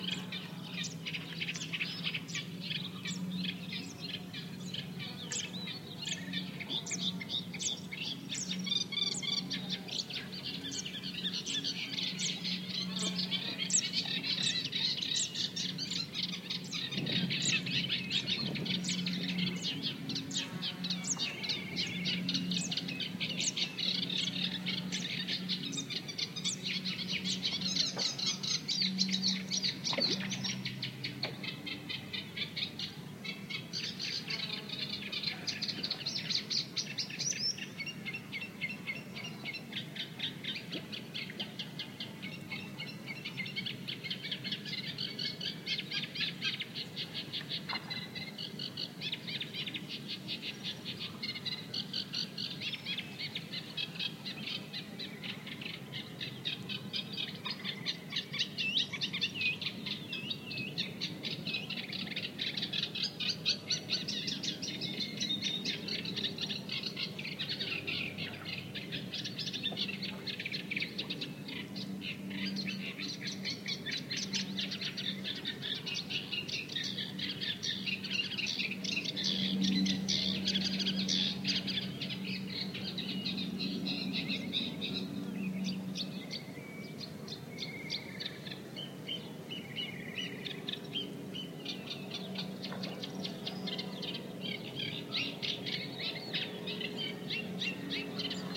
描述：柳树和荆棘的密集树篱内的氛围。人们可以听到昆虫飞翔（和咬我！）以及夜莺的声音。Soundman OKM >iRiver H120。/ambiente dentro de un seto denso de zarzas y mimbreras, con sonido de insectos y ruiseñores< /p>
标签： 道纳拿 双耳 现场记录 昆虫 性质 氛围 春天
声道立体声